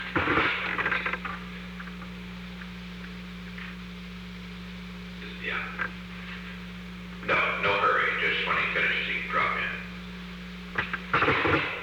Secret White House Tapes
Location: Oval Office
The President talked with an unknown person in Henry A. Kissinger's office[?].